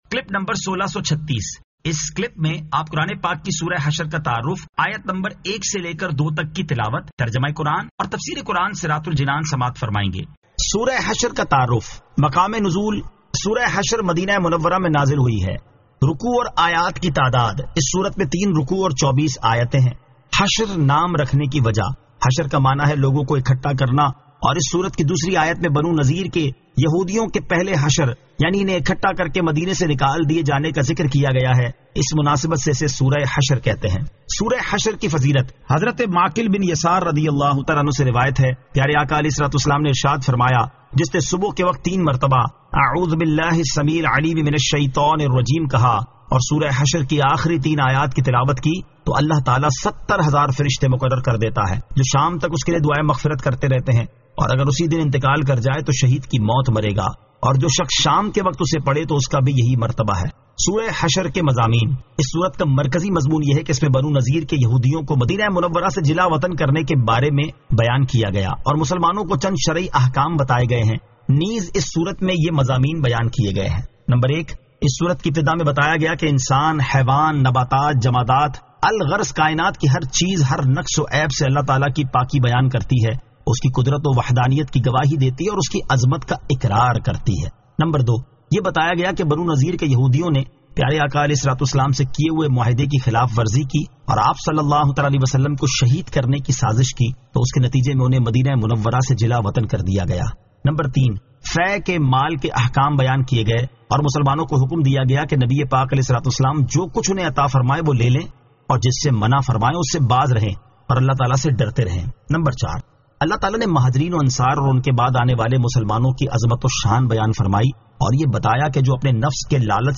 Surah Al-Hashr 01 To 02 Tilawat , Tarjama , Tafseer
2024 MP3 MP4 MP4 Share سُوَّرۃُ الحَشَرٗ آیت 01 تا 02 تلاوت ، ترجمہ ، تفسیر ۔